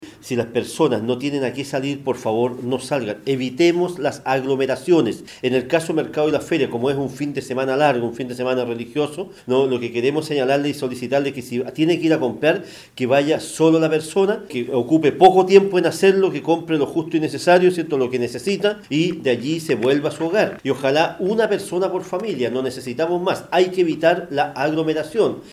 CUÑA-ALCALDE-PAREDES-1.mp3